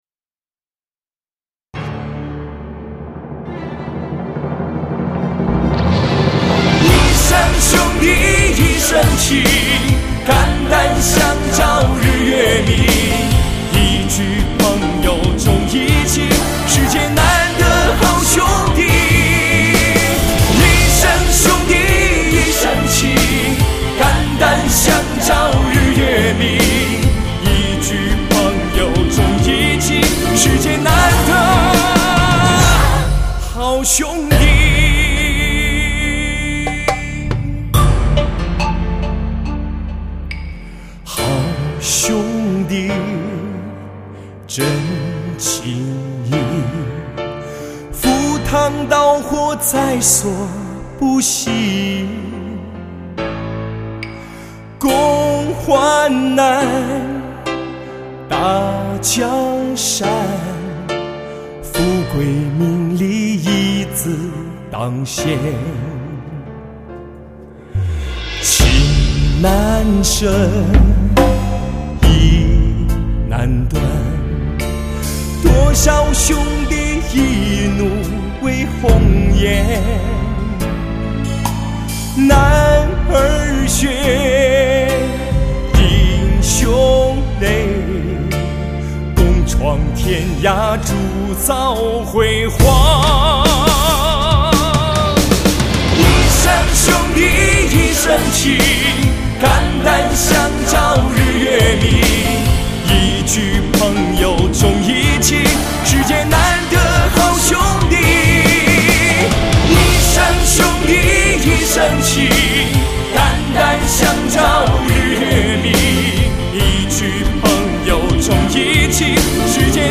以钢琴弦乐交相辉映为歌曲增添几分凝重。